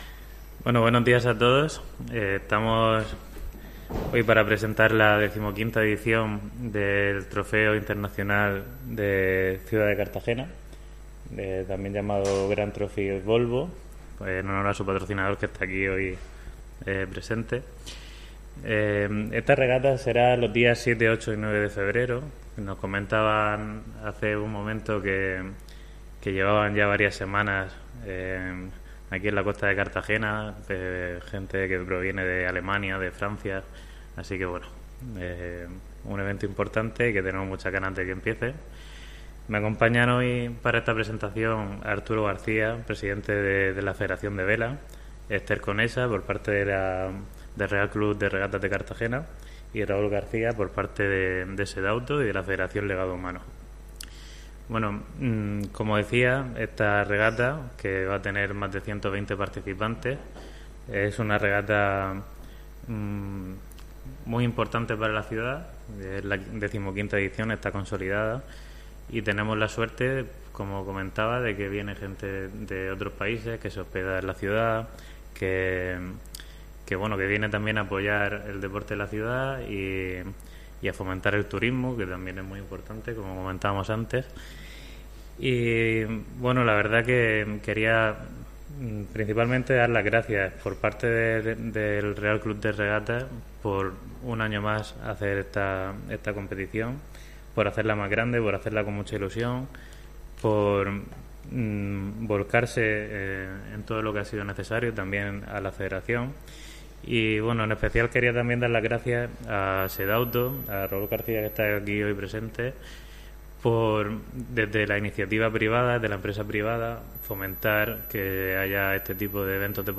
Enlace a Presentación del Trofeo Internacional Ciudad de Cartagena Optimist